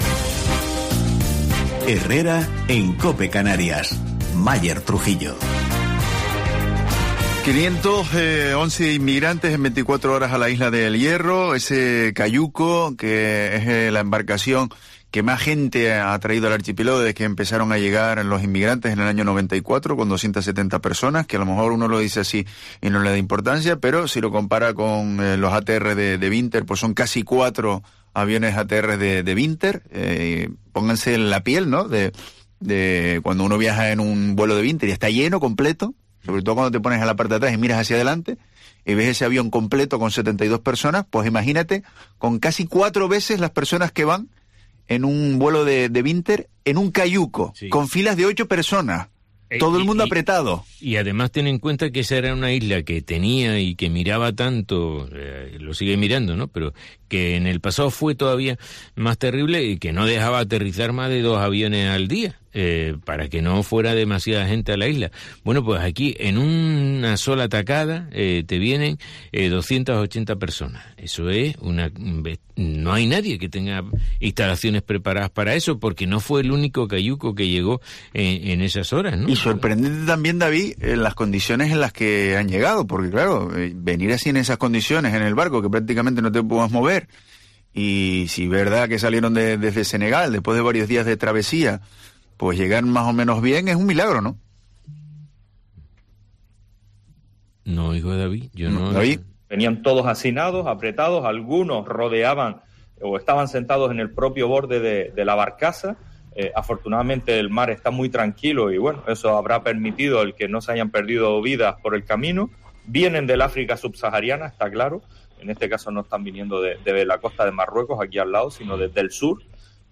En una entrevista en Herrera en COPE Canarias , Alpidio Armas (PSOE) ha acusado al Gobierno de Canarias (CC-PP) de hacer "dejación de funciones" al no derivar adecuadamente los menores a otras islas.